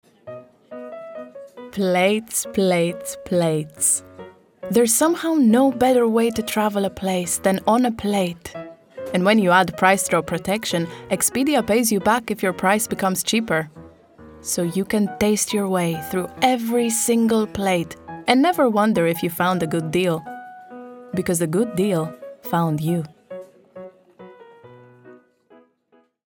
Greek, Female, 20s-30s
English (Greek Accent)